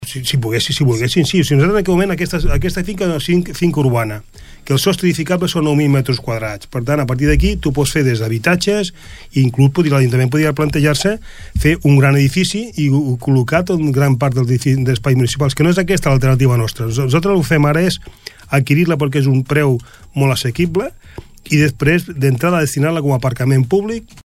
A la mateixa entrevista de Ràdio Palafrugell, Juli Fernández preveu que el nou aparcament del carrer Pi i Maragall estigui a punt a l’estiu, i la finca on s’ubicarà s’adquirirà durant la primera meitat de l’any.